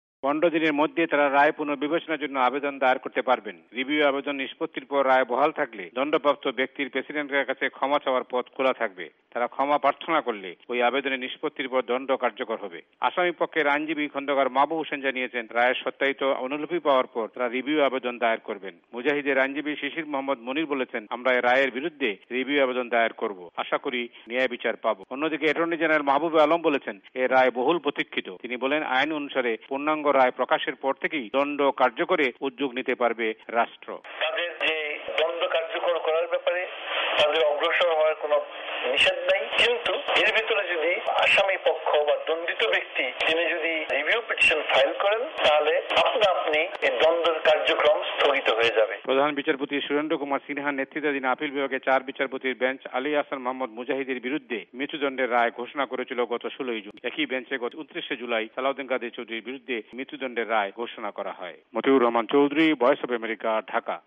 প্রতিবেদন